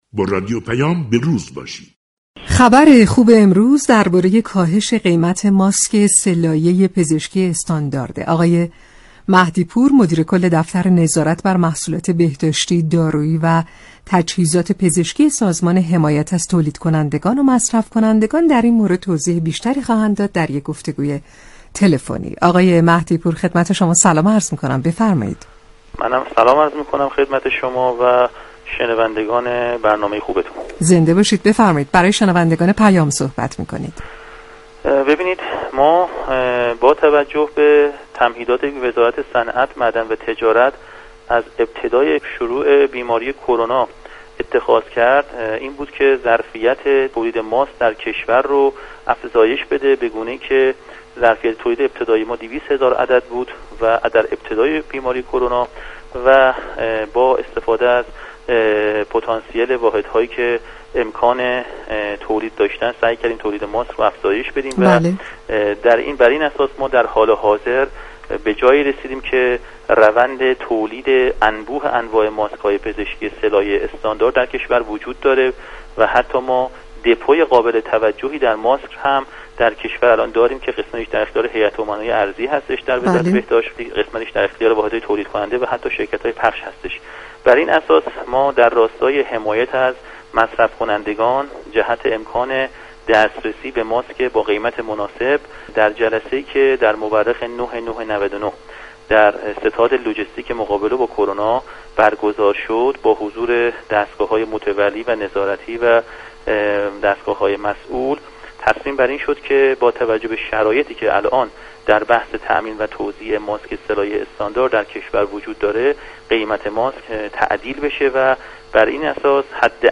مهدی‌پور ، مدیركل نظارت بر محصولات بهداشتی، دارویی و تجهیزات پزشكی سازمان حمایت از مصرف كنندگان و تولید كنندگان در گفتگو با رادیو پیام ، از ارزان شدن قیمت ماسك سه لایه پزشكی با نرخ مصوب ١٠٠٠ تومان خبر داد .